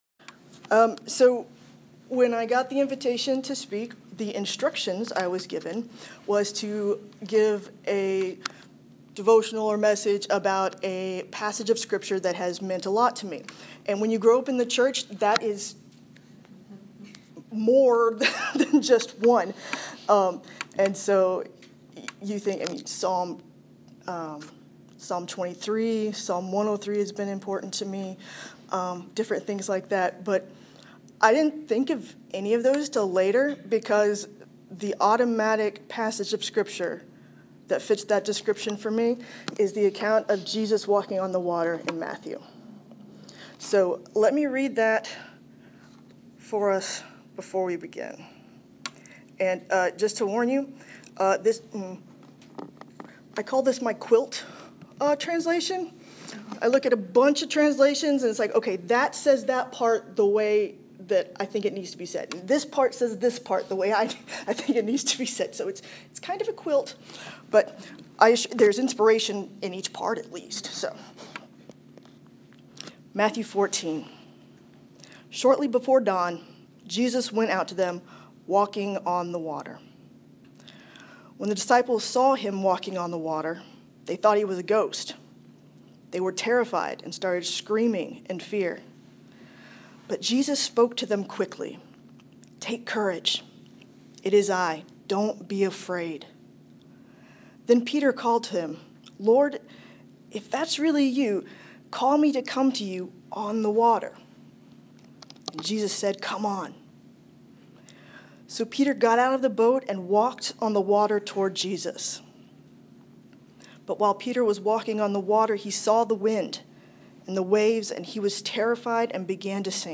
Northern-chapel-walking-on-water.m4a